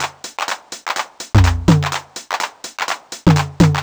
cch_percussion_loop_leader_125.wav